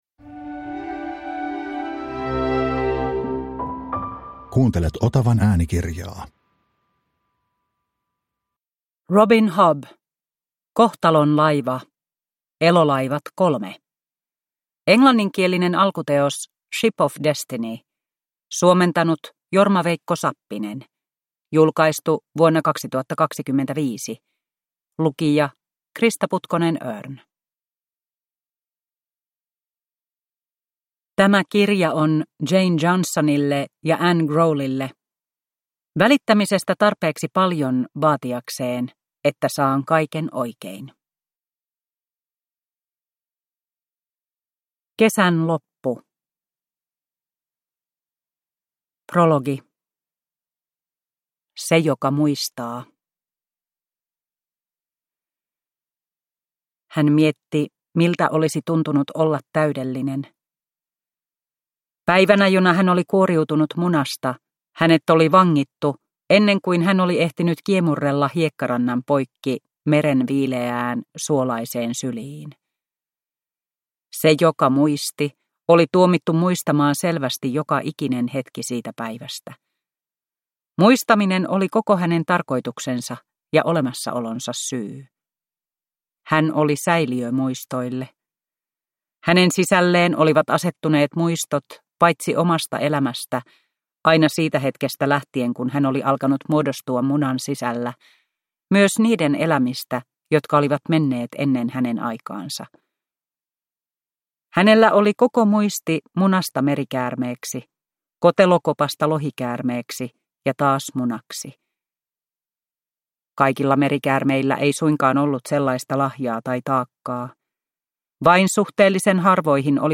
Kohtalon laiva (ljudbok) av Robin Hobb